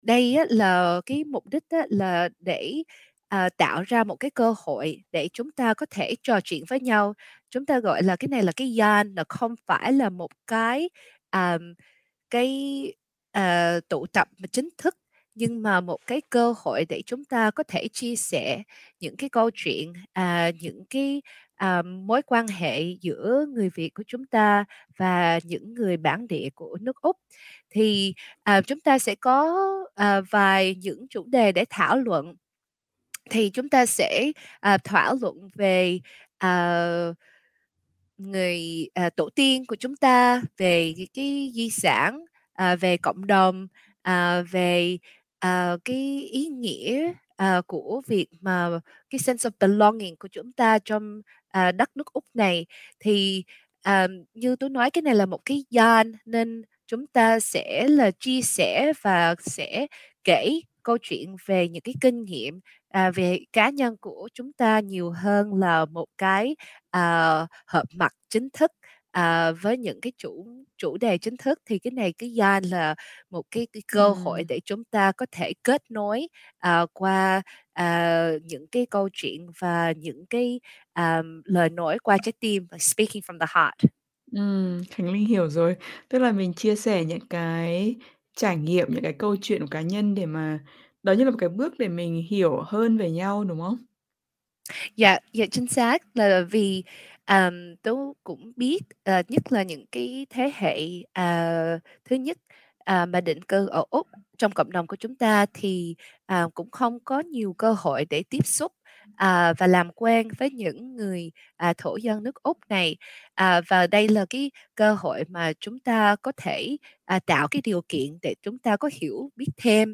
Trò chuyện với SBS Tiếng Việt